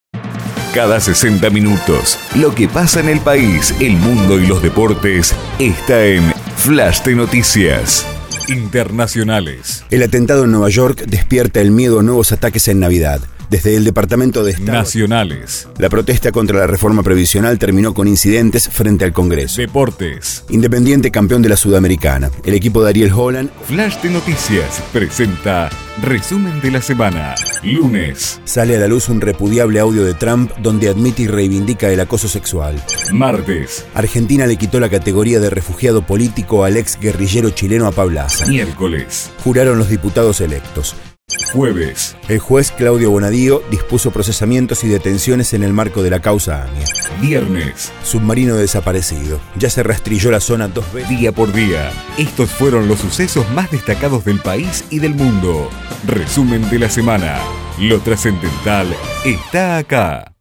Flash de Noticias es un servicio de flashes para emisoras de radio.
Apertura y cierre personalizados. Alta calidad artistica y de produccion